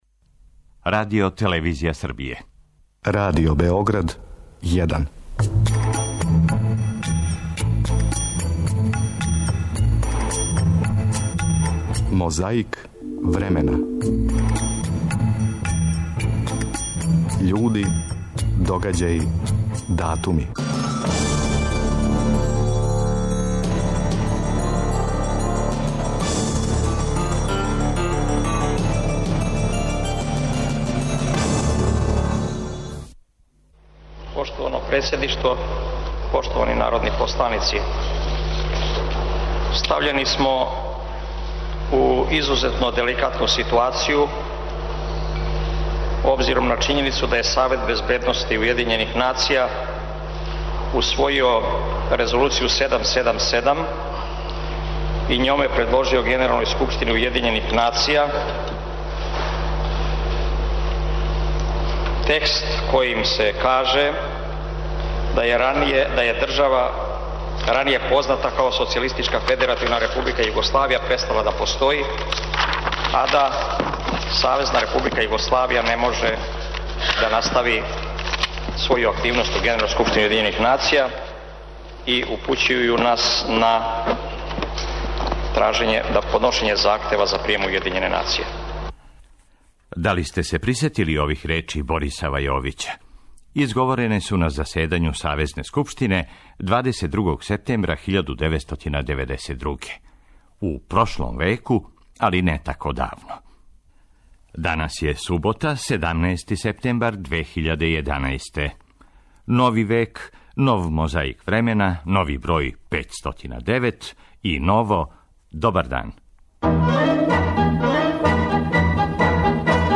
Мозаик времена сложен је од тонских записа који могу да послуже као репрезентативни примери једне епохе, једног мишљења, једног говора, једне идеје, једне демагогије...Јер, иако се то на примеру Србије не види, на грешкама се учи.